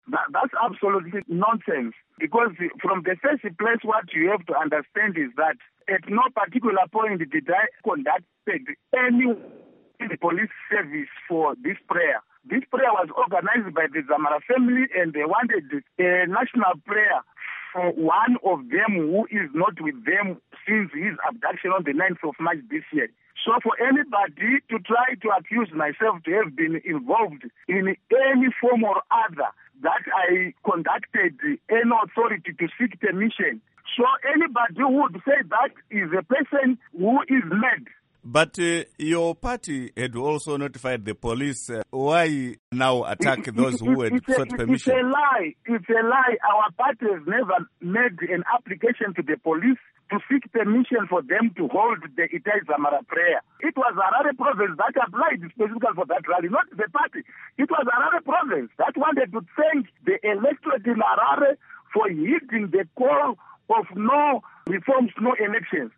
Interview With Job Sikhala